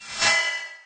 whoosh_fast_1.ogg